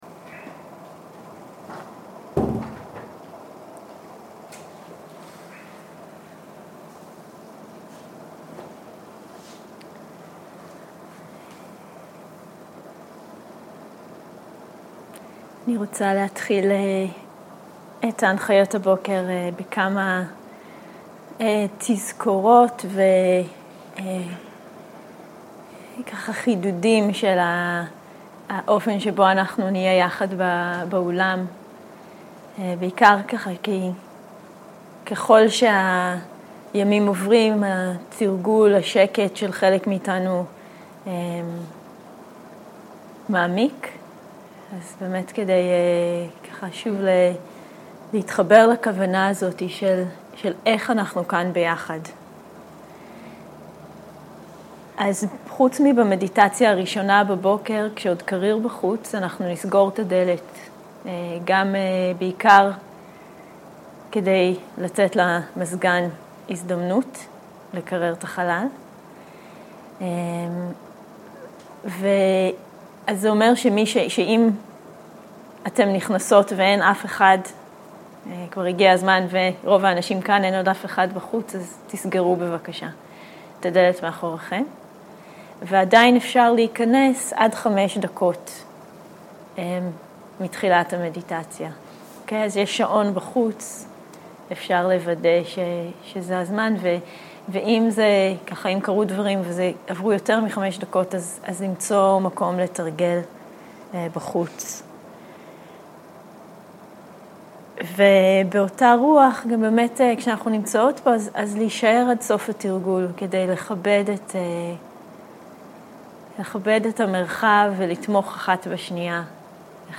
הנחיות לעבודה עם כאב וחוסר נוחות, כולל חוסר מנוחה ועייפות 0:00 0:00 סוג ההקלטה: Dharma type: Guided meditation שפת ההקלטה: Dharma talk language: Hebrew